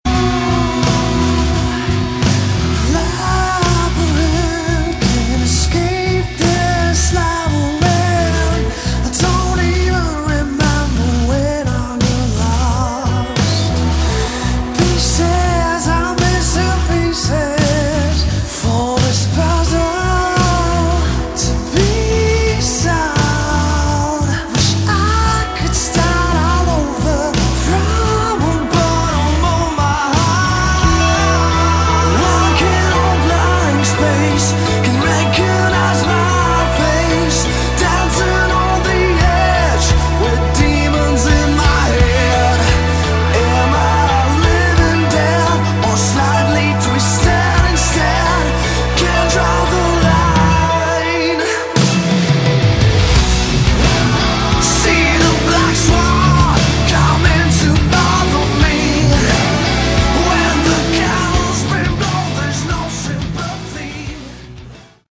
Category: Hard Rock
vocals
guitar
bass
drums
cello
saxophone